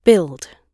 build-gb.mp3